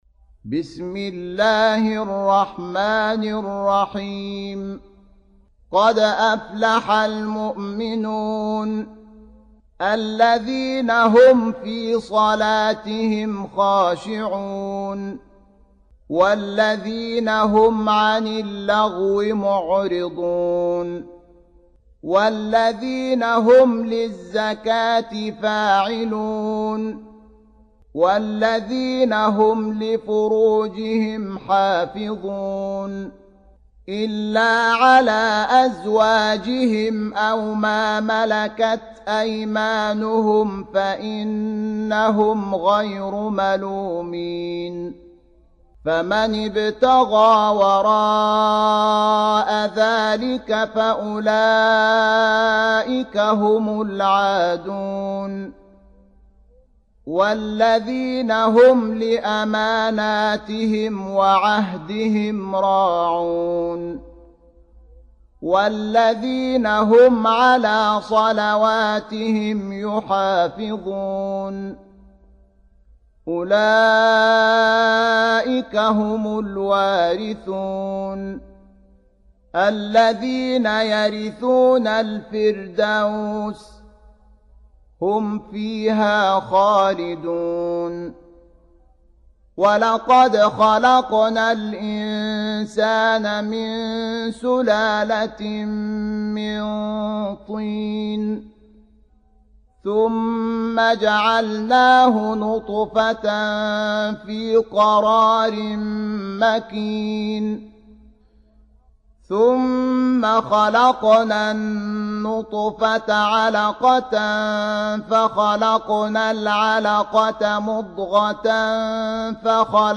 23. Surah Al-Mu'min�n سورة المؤمنون Audio Quran Tarteel Recitation
Surah Repeating تكرار السورة Download Surah حمّل السورة Reciting Murattalah Audio for 23.